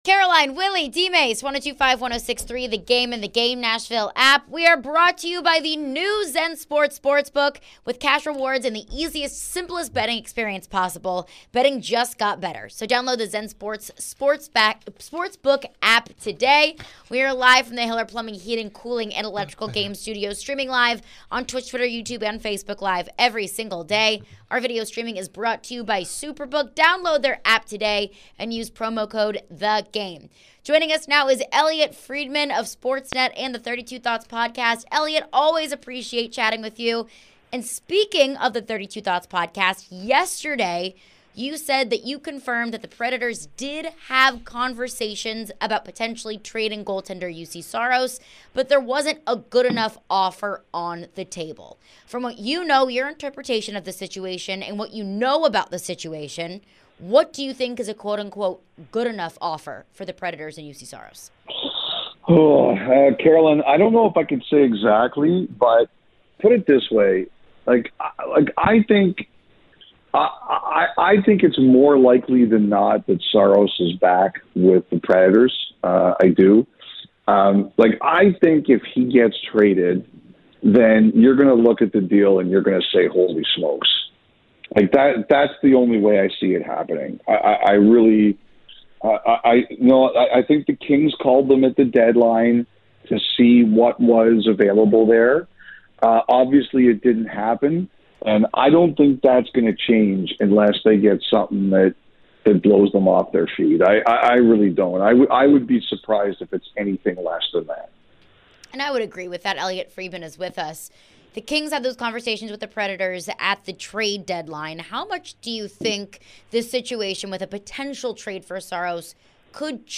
Elliotte Friedman Interview (6-8-2023)